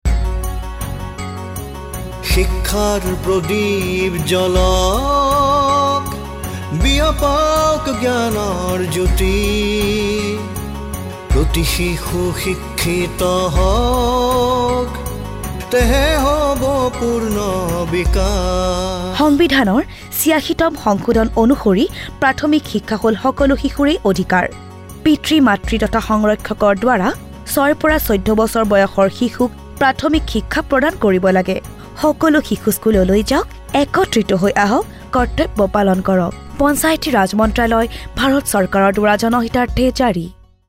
35 Fundamental Duty 11th Fundamental Duty Duty for all parents and guardians to send their children in the age group of 6-14 years to school Radio Jingle Assamese